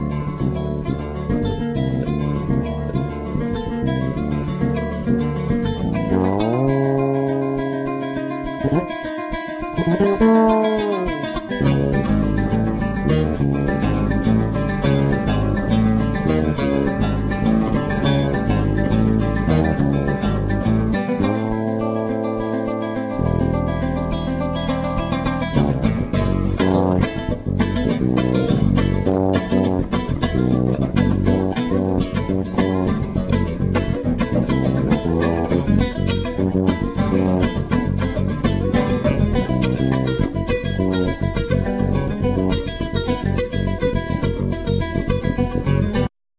Guitar
Violin
Keyboards
Sax
Trumpet
Trombone